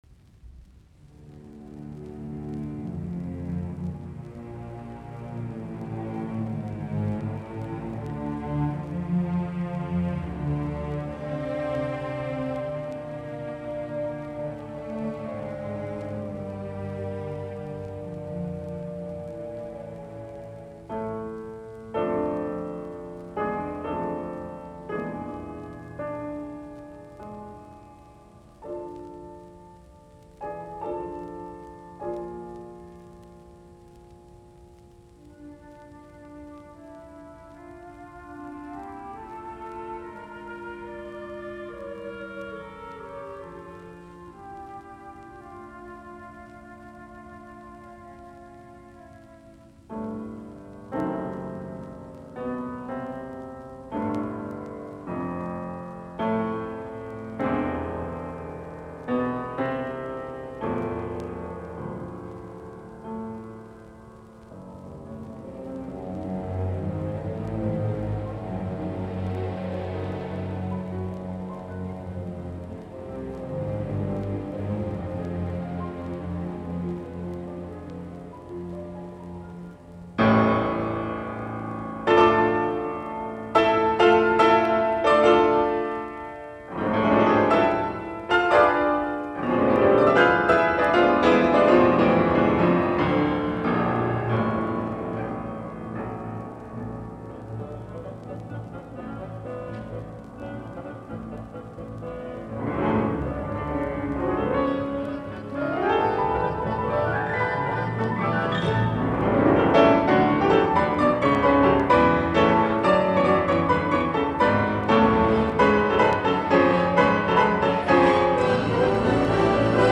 musiikkiäänite